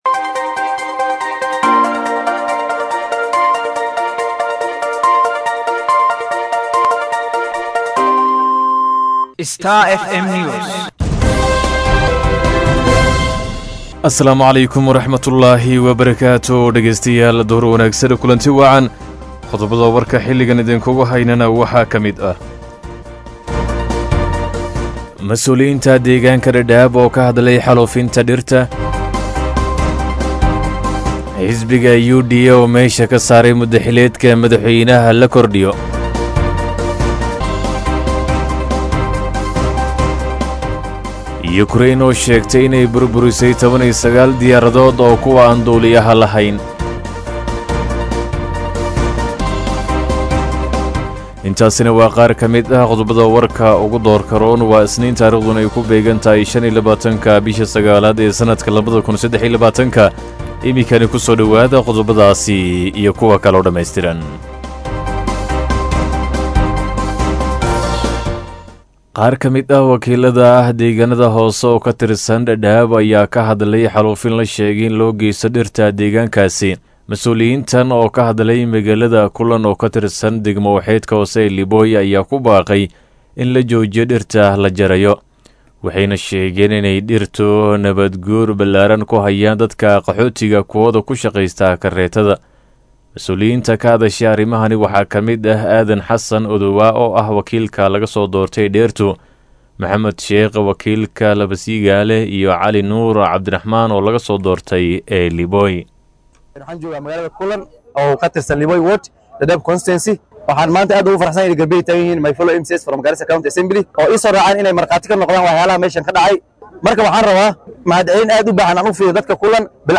DHAGEYSO:WARKA DUHURNIMO EE IDAACADDA STAR FM